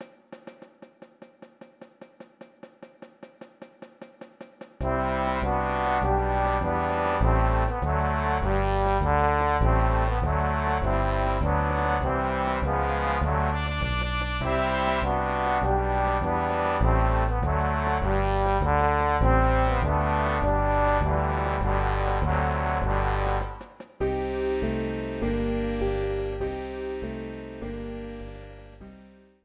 Category: Big Jazz Band
Genre: Jazz - "Mod" Graduation March